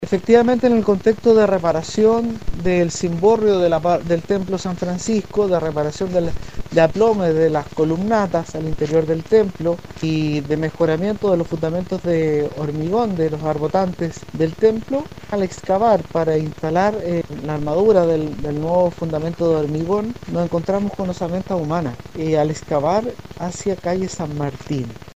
Fueron trabajadores quienes encontraron las osamentas mientras efectuaban labores de reparación de la Iglesia San Francisco de Castro, en el marco del proyecto de restauración que se desarrolla en el templo de la capital chilota, por lo que se procedió dar cuenta del hecho a Carabineros quienes acordonaron el área y se paralizó la obra, según lo detalla el Gobernador de Chiloé, Fernando Bórquez.